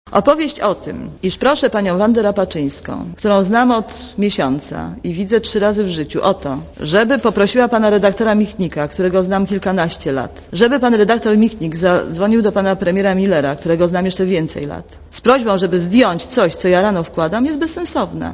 Wypowiedź Aleksandry Jakubowskiej
jakubowskatelefon.mp3